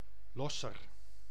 Losser (Dutch pronunciation: [ˈlɔsər]